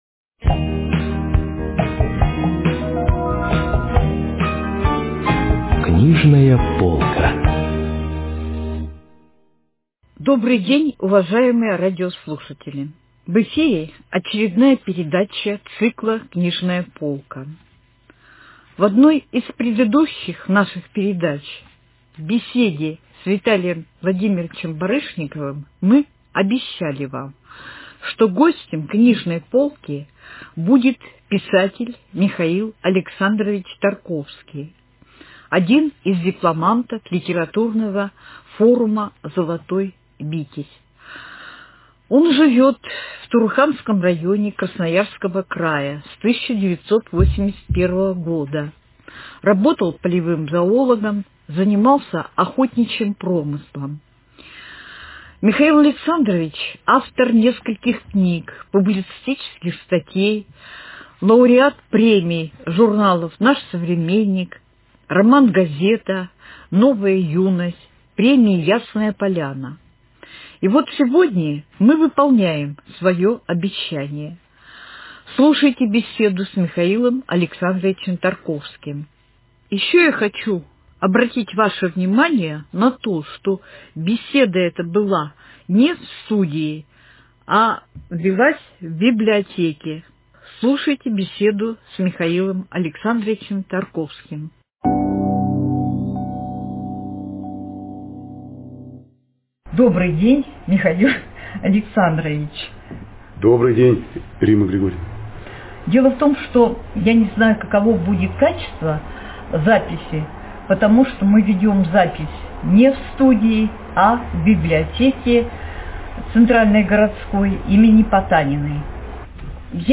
Выпуск посвящен творчеству нашего современника, сибирского писателя Михаила Тарковского. Беседа с ним записана в стенах библиотеки им. Потаниной.